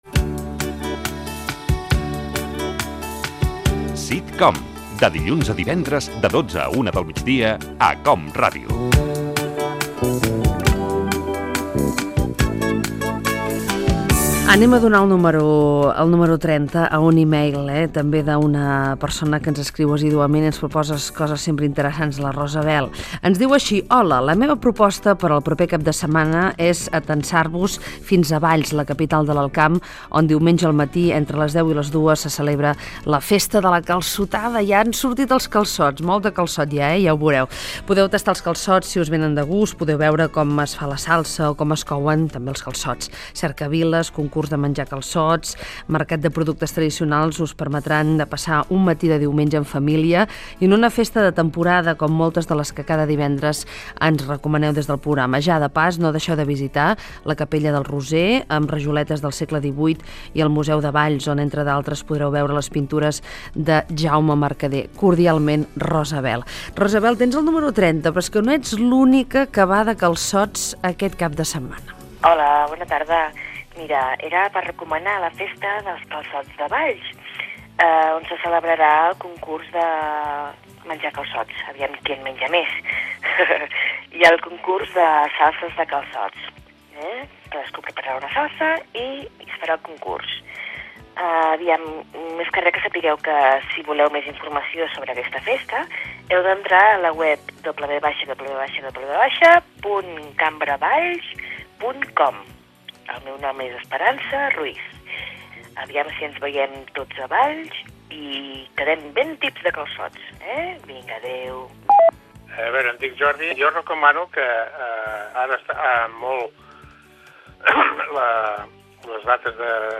Indicatiu del programa, proposta dels oïdors per al cap de setmana: calçotada a Valls,visita a Sant Pere de Roda.
Entreteniment
Fragment extret de l'arxiu sonor de COM Ràdio